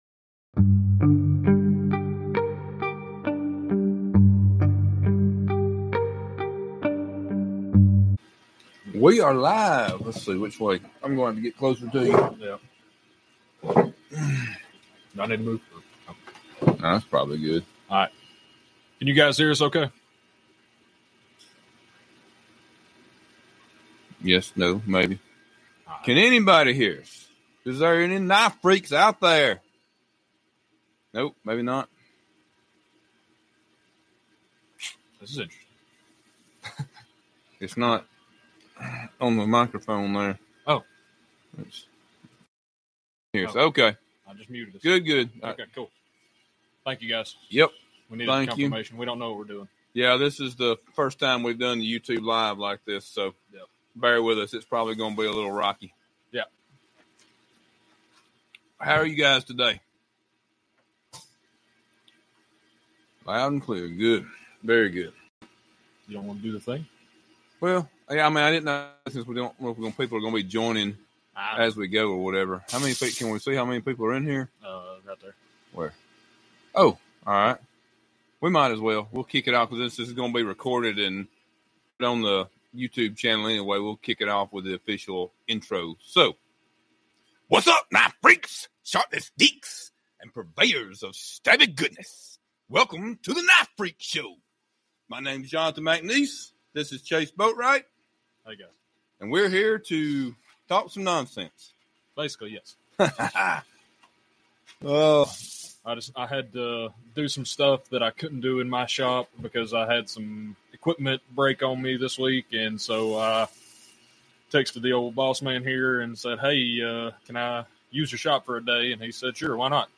Youtube Livestream from 1/31/2025